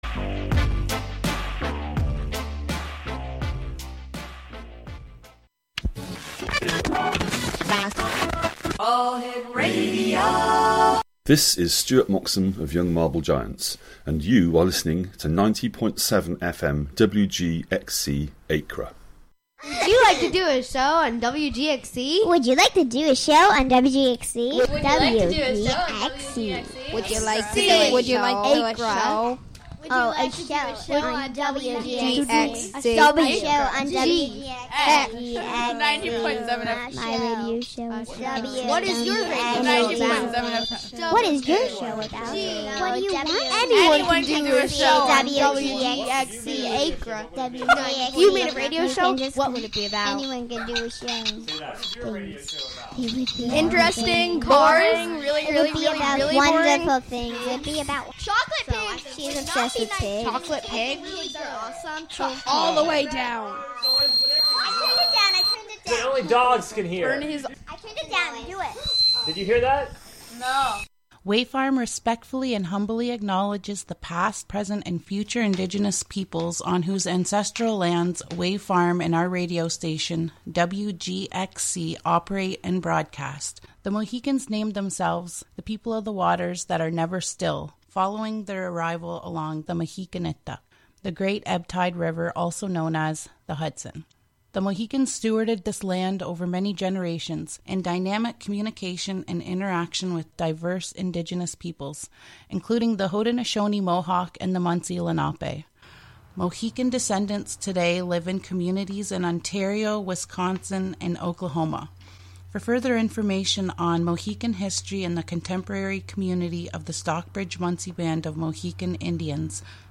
just the fictions read for the listener as best as i can read them.